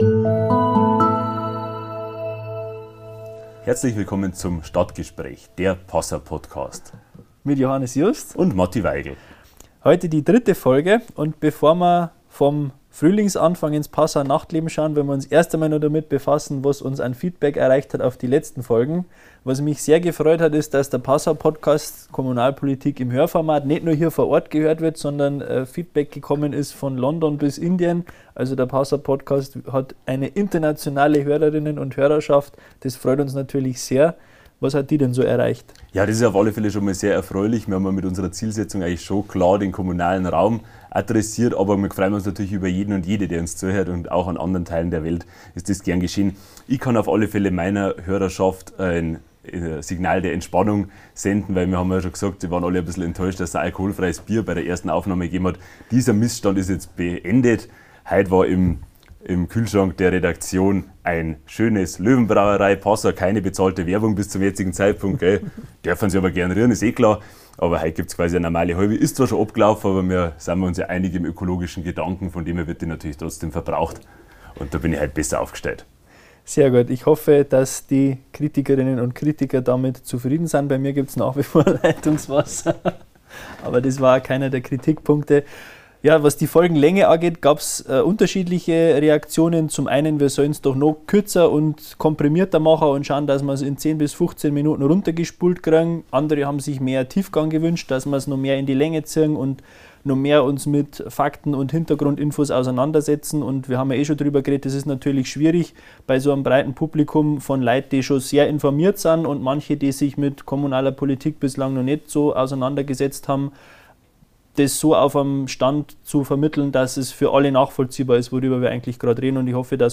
Matthias Weigl (Grüne) und Johannes Just (SPD), die zwei jüngsten Passauer Stadträte, im „Stadtgespräch“ – über das Nachtleben, das im Neumarkt schwindet und am Spitzberg wächst, über bessere Angebote für den Radverkehr sowie über Hiobsbotschaften und Visionen zu Donaubrücken.